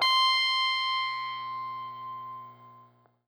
SPOOKY    AZ.wav